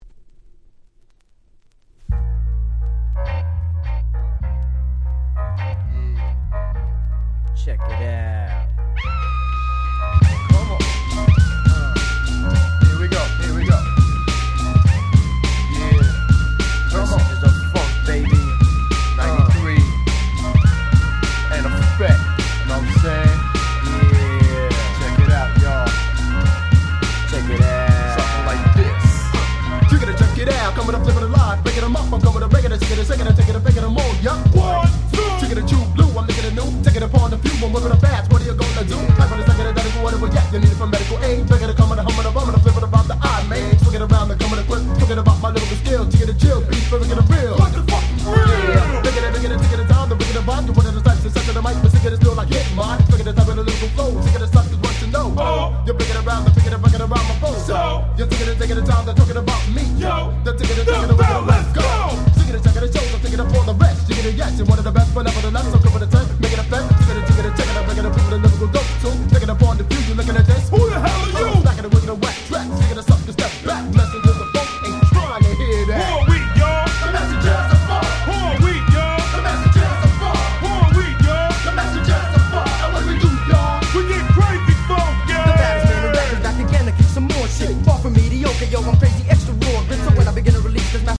93' Nice Hip Hop !!
イケイケNew Schoolチューンです！！
メッセンジャズオブファンク 90's Boom Bap ブーンバップ